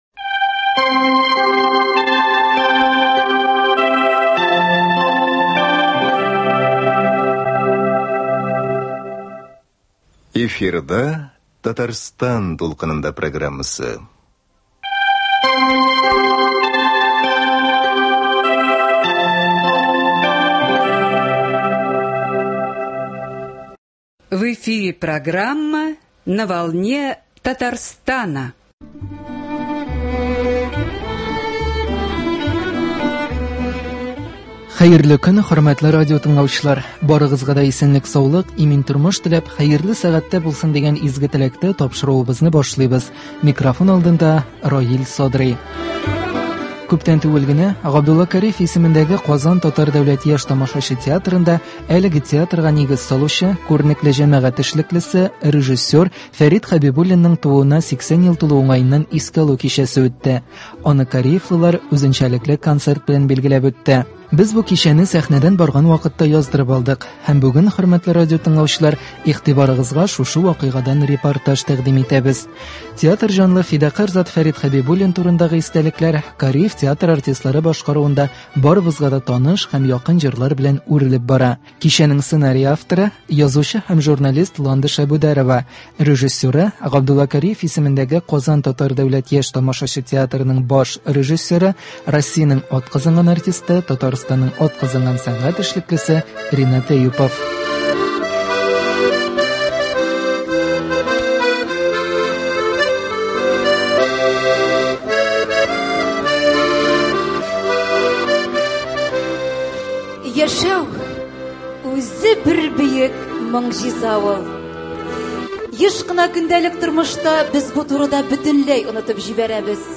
Аны кариевлылар үзенчәлекле концерт белән билгеләп үтте. Без бу кичәне сәхнәдән барган вакытта яздырып алдык һәм бүген, хөрмәтле радиотыңлаучылар, игътибарыгызга шушы вакыйгадан репортаж тәкъдим итәбез.